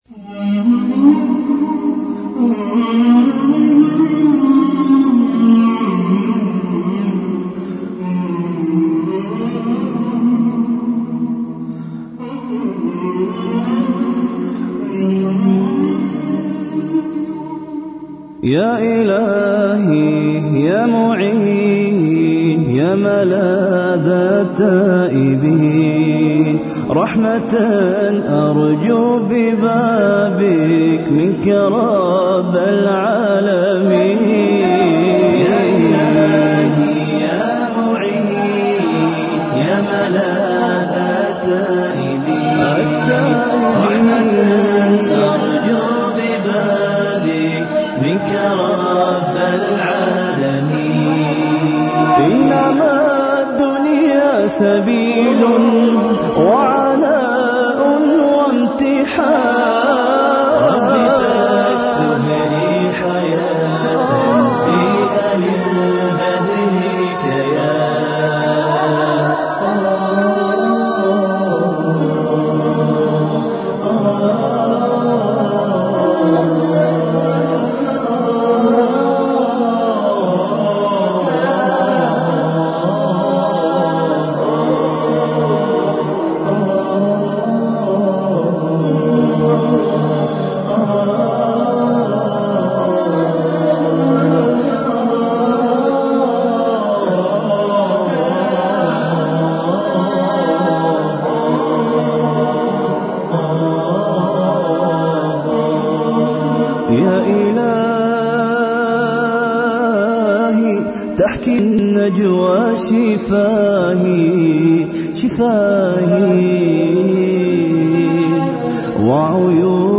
النشيدة